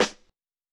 Snare Main Wilshire.wav